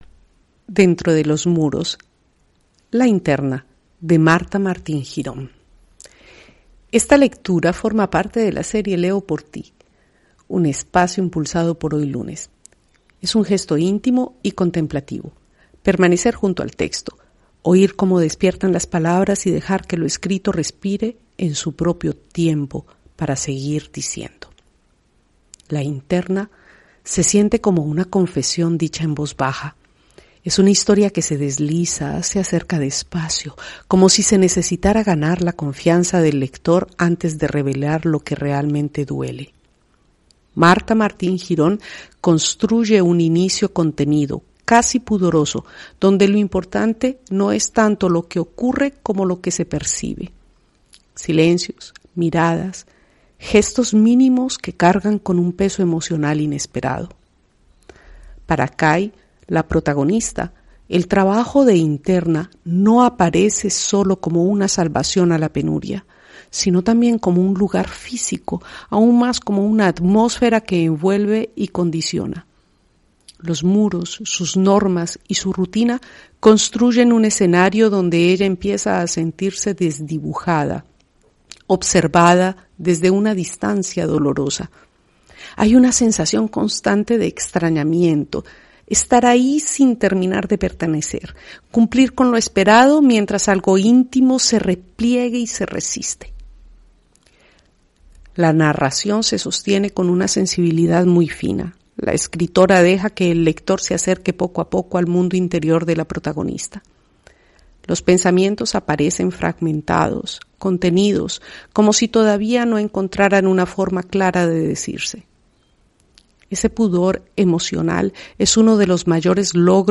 HoyLunes – Esta lectura forma parte de la serie ‘Leo por ti’, un espacio impulsado por HoyLunes. Es un gesto íntimo y contemplativo, permanecer junto al texto, oír cómo despiertan las palabras y dejar que lo escrito respire en su propio tiempo, para seguir diciendo.